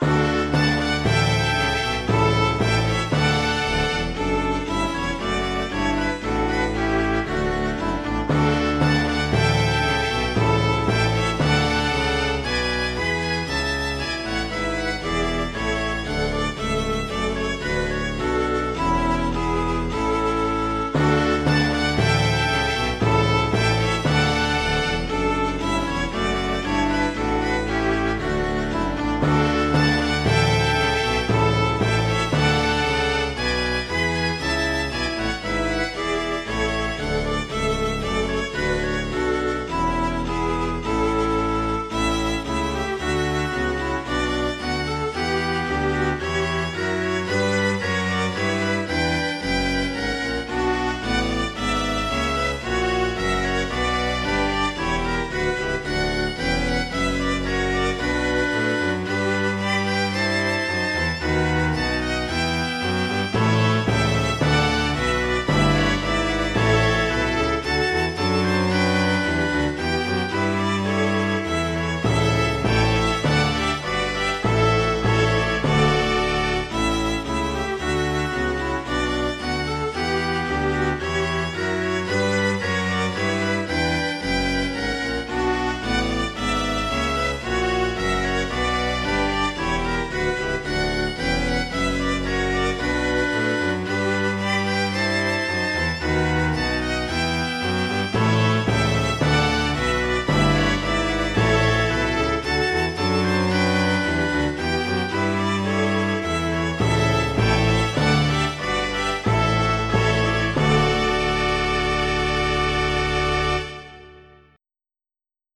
MIDI Music File
2 channels
gavotte.mp3